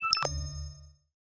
Futuristic Sounds (29).wav